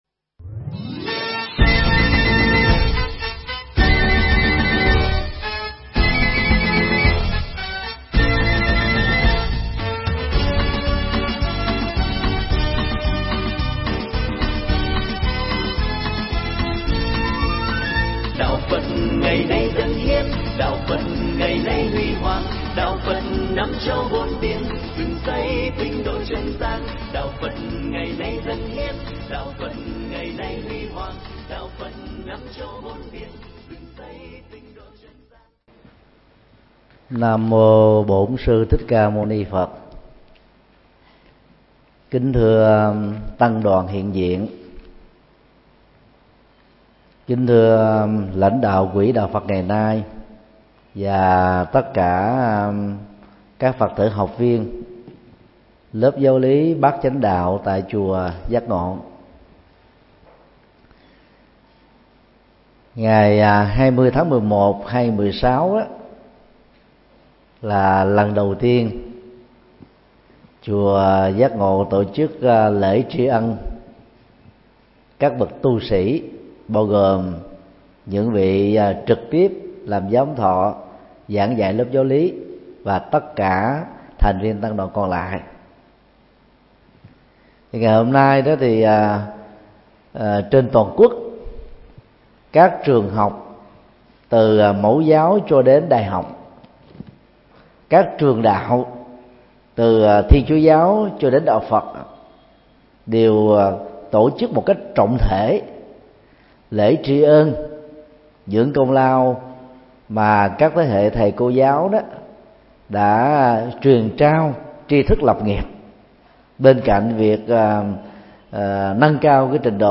Mp3 Pháp Thoại Tôn Sư Trọng Đạo – Thượng Tọa Thích Nhật Từ giảng tại chùa Giác Ngộ nhân ngày Nhà giáo Việt Nam 20 tháng 11 năm 2016